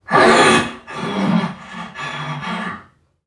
NPC_Creatures_Vocalisations_Robothead [27].wav